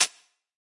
来自我的卧室的声音" Cable drop 3 ( Freeze )
描述：在Ableton中录制并略微修改的声音